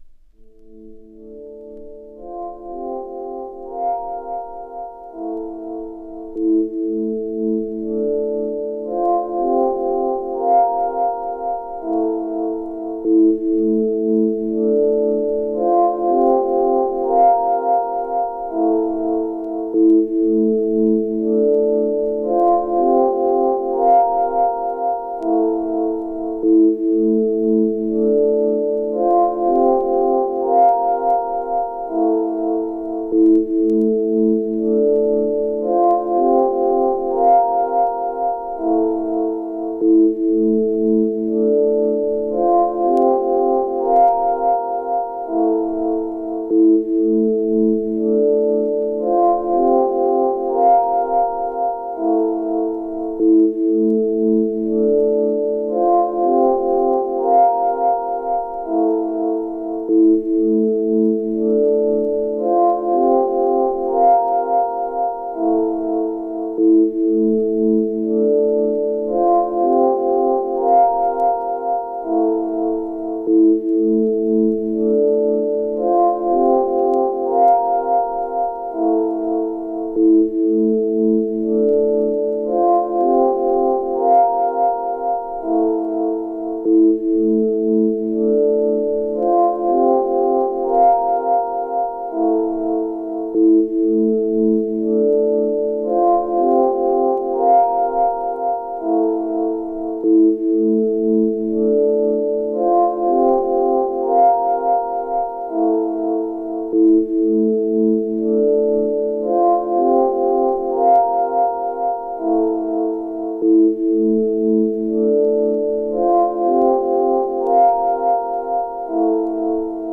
白ラベル仕様の限定LPで、淡くメランコリックなシンセ・ミニマリズムを収録。
太陽の光が差し込まない、どこか冷たく美しい北欧の風景を思わせるサウンド。
loner synth / contemplative ambient